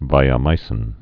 (vīə-mīsĭn)